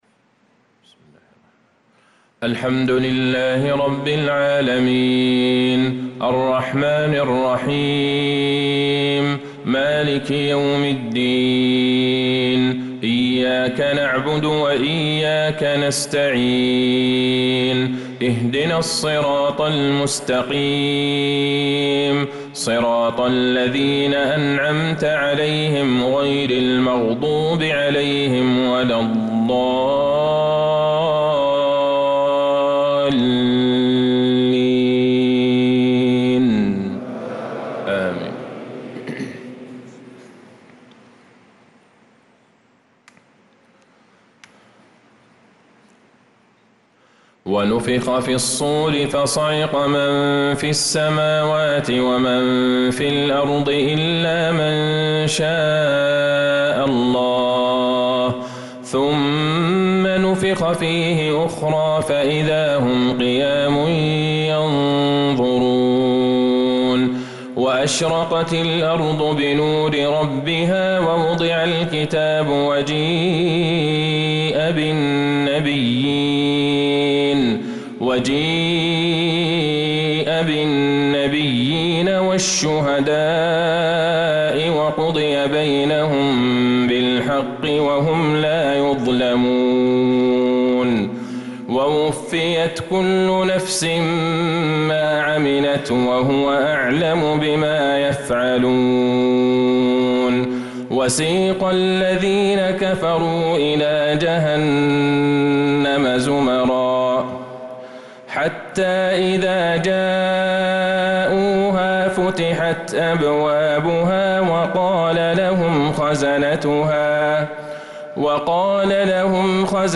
صلاة العشاء للقارئ عبدالله البعيجان 4 ربيع الأول 1446 هـ
تِلَاوَات الْحَرَمَيْن .